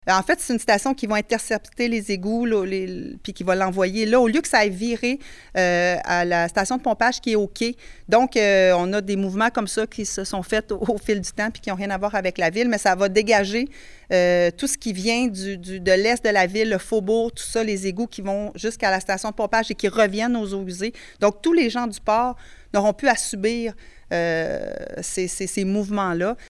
Lors de la conférence de presse de lundi annonçant le budget, la mairesse, Geneviève Dubois, a parlé d’une rigueur budgétaire.